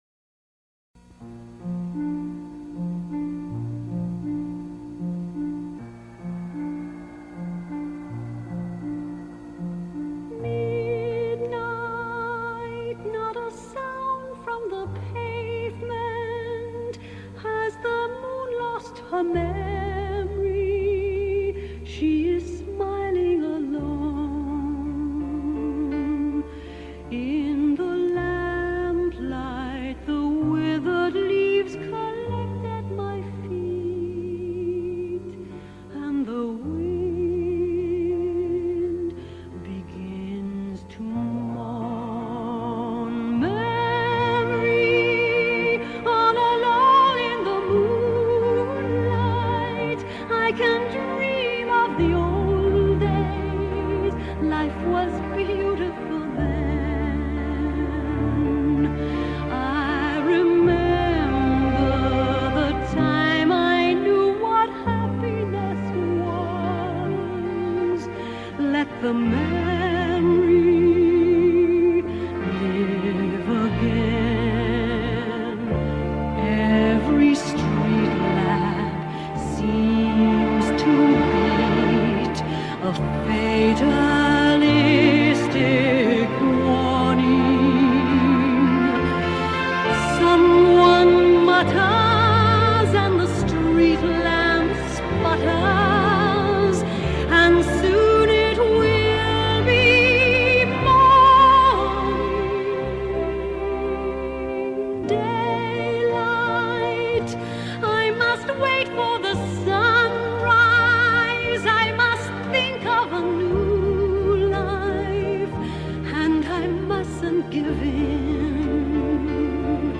Ο Δημήτρης Π. Σωτηρόπουλος, Πρόεδρος των Γενικών Αρχείων του Κράτους και Καθηγητής Σύγχρονης Πολιτικής Ιστορίας του Πανεπιστημίου Πελοποννήσου, περιγράφει στην εκπομπή «Τα Πρόσωπα της Εβδομάδας»